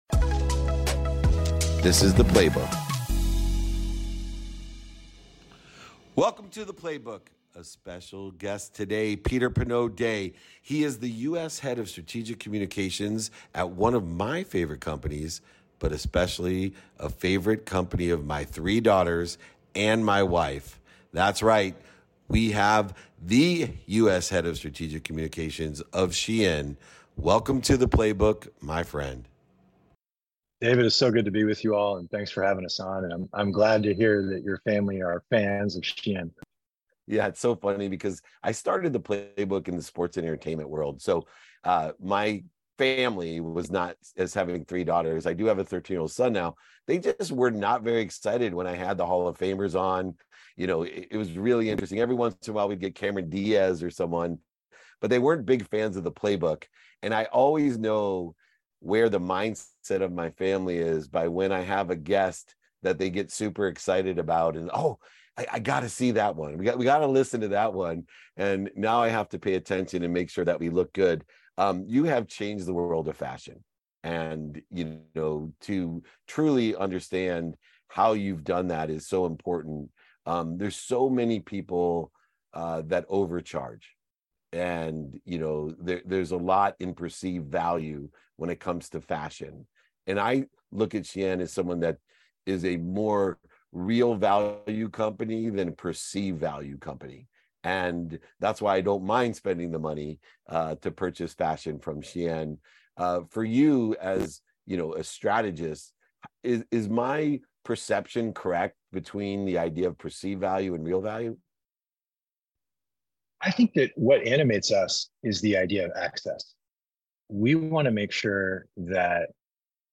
On today’s episode of The Playbook, I had a conversation